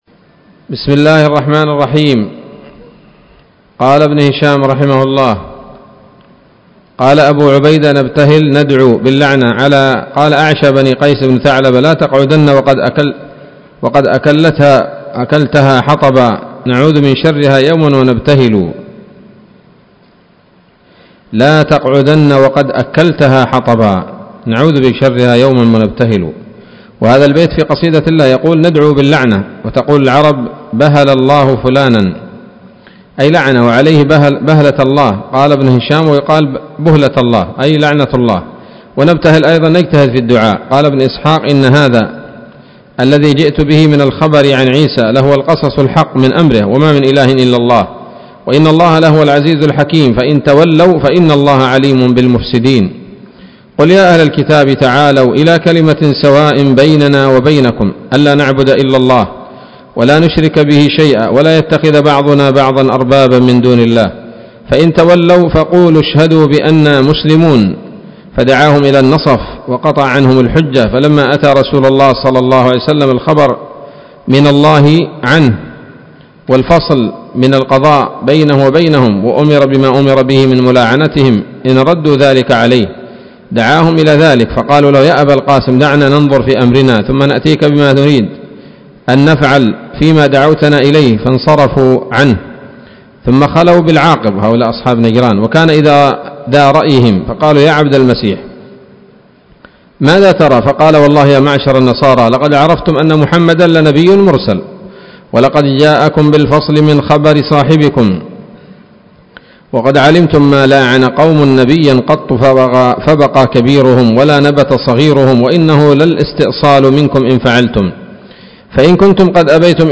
الدرس الأول بعد المائة من التعليق على كتاب السيرة النبوية لابن هشام